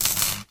belle_reload_01.ogg